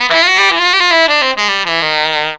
HARSH SCALE.wav